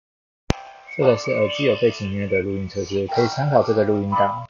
根據我實測結果我的看法是～我只能說抗噪確實不錯各位可以聽看看我錄製的 4 組參考錄音檔，有背景音樂的部分我故意找之前做有破音的音樂來測試
▶ 有背景音樂錄音檔
● 耳機收音